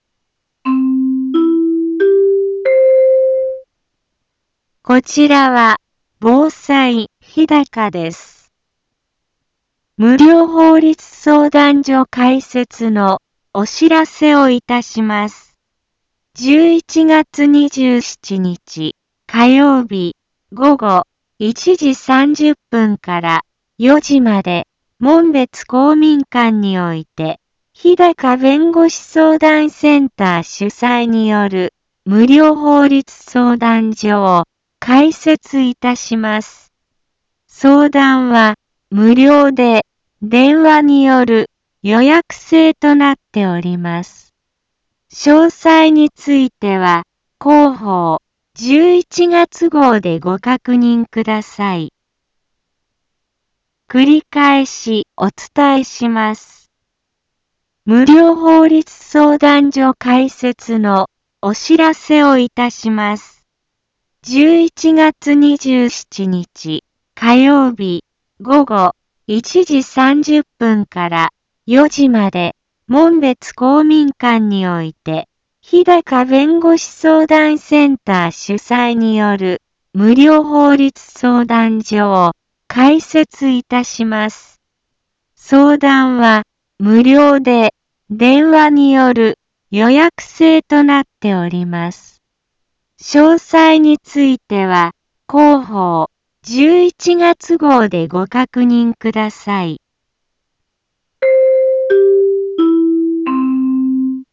一般放送情報
Back Home 一般放送情報 音声放送 再生 一般放送情報 登録日時：2018-11-19 15:03:40 タイトル：無料法律相談会のお知らせ インフォメーション：無料法律相談所開設のお知らせをいたします。